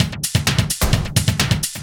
Index of /90_sSampleCDs/Ueberschall - Techno Trance Essentials/02-29 DRUMLOOPS/TE20-24.LOOP-ADDON+HIHAT/TE20.LOOP-ADDON2